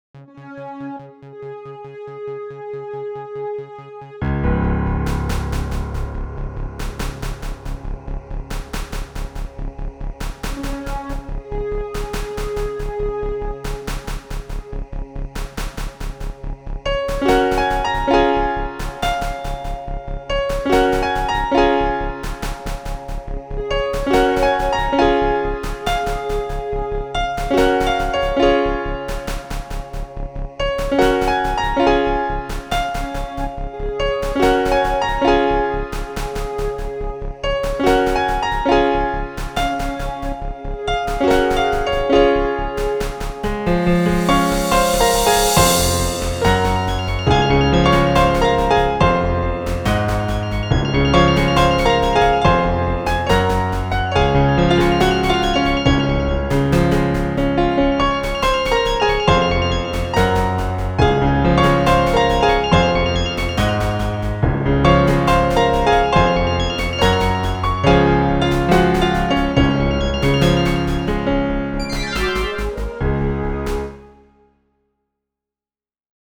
Played on a Lowrey Stardust